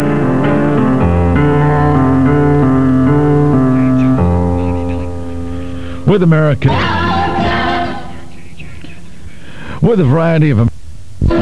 These are sound clips of some of my airchecks.
KJWL-99.3 ID's On WLIP-1050